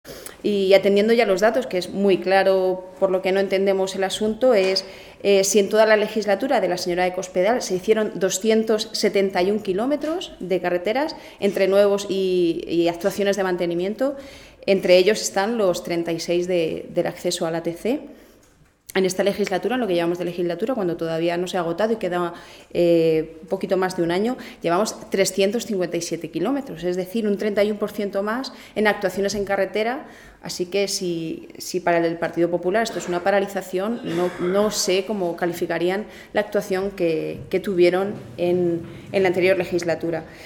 La diputada del Grupo Parlamentario Socialista en las Cortes de Castilla-La Mancha, Rosario García, ha destacado la buena marcha de las obras en carreteras que se está llevando a cabo por parte del gobierno del presidente García-Page.
Cortes de audio de la rueda de prensa